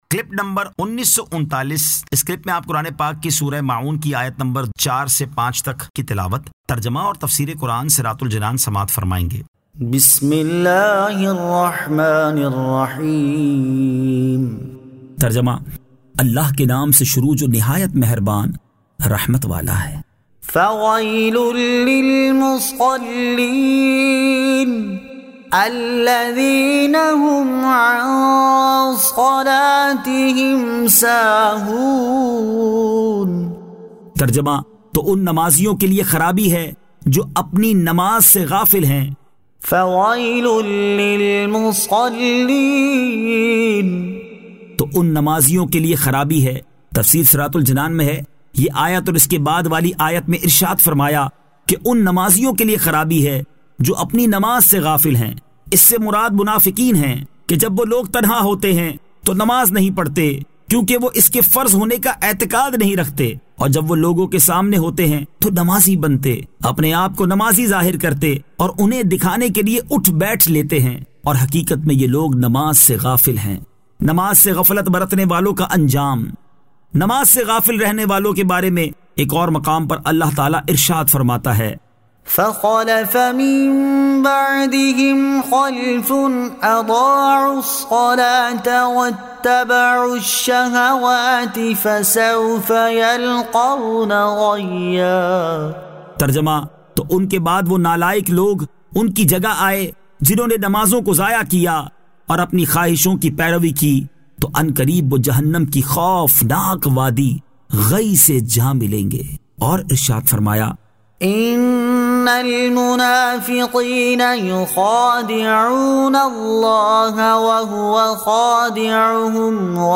Surah Al-Ma'un 04 To 05 Tilawat , Tarjama , Tafseer